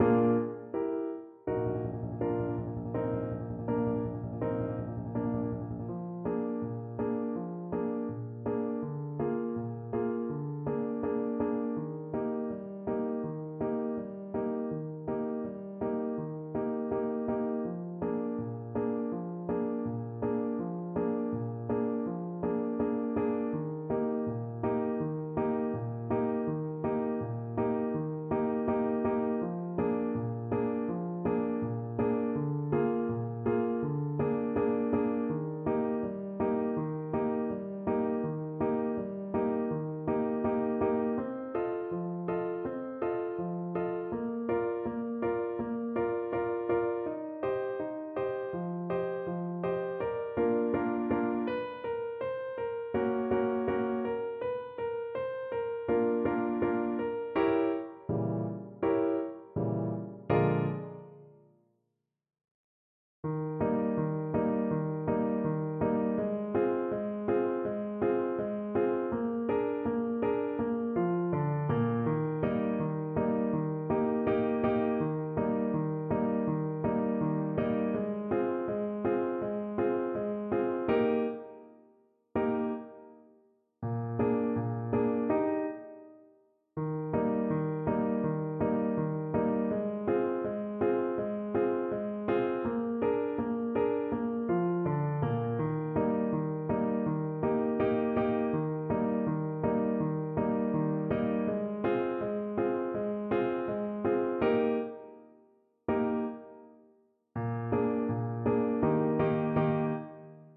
Play (or use space bar on your keyboard) Pause Music Playalong - Piano Accompaniment Playalong Band Accompaniment not yet available transpose reset tempo print settings full screen
Clarinet
2/4 (View more 2/4 Music)
Arrangement for Clarinet and Piano
Eb major (Sounding Pitch) F major (Clarinet in Bb) (View more Eb major Music for Clarinet )
Molto allegro
Polkas for Clarinet